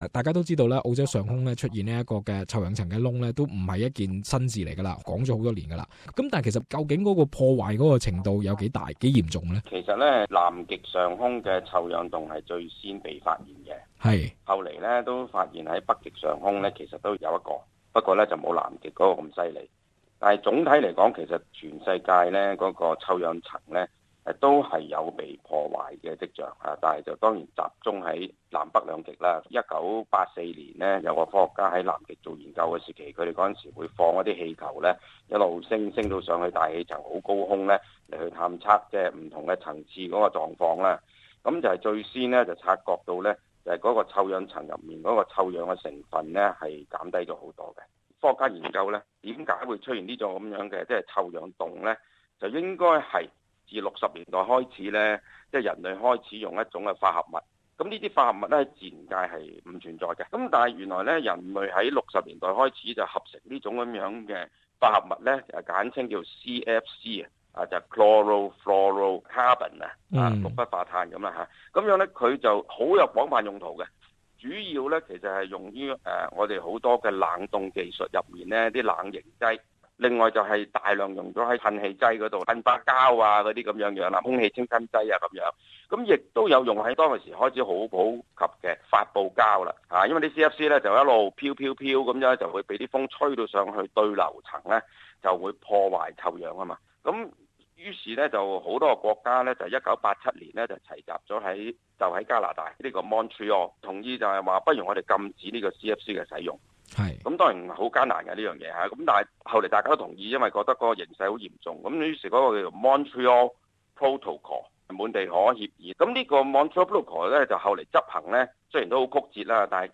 访问 :臭氧层开始自动愈合